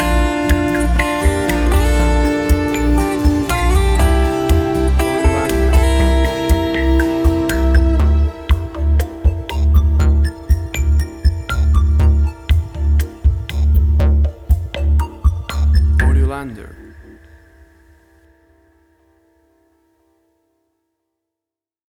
WAV Sample Rate: 16-Bit stereo, 44.1 kHz
Tempo (BPM): 60